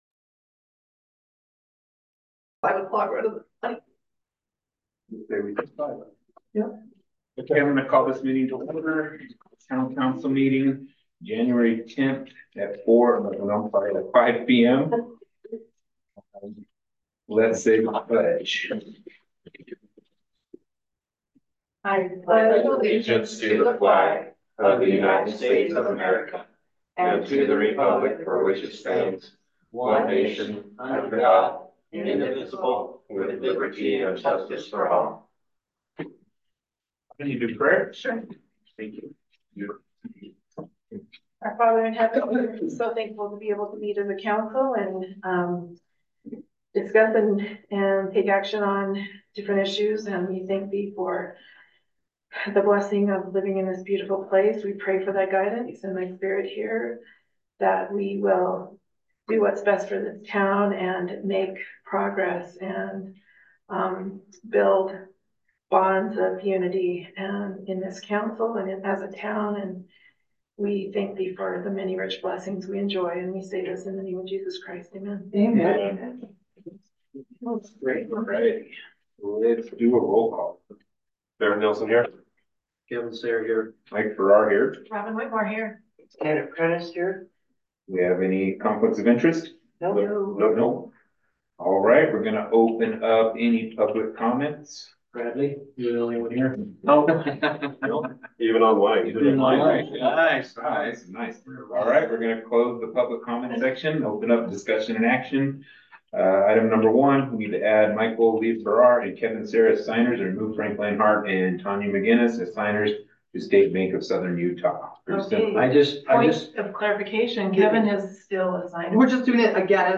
Town Council Meeting
In accordance with state statute, one or more council members may be connected via speakerphone.